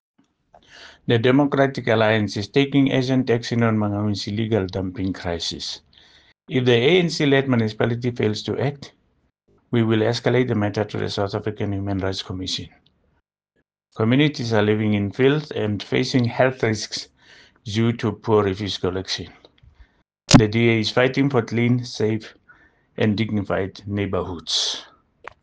Sesotho soundbites by Cllr Edwin Maliela.